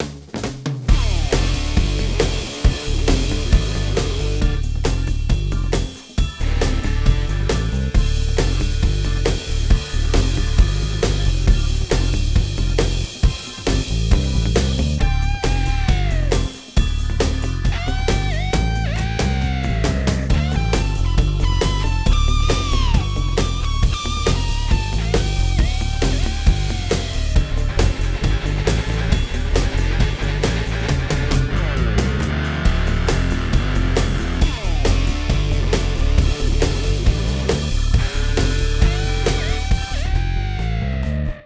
Rock (bucle)
melodía
repetitivo
rítmico
rock
sintetizador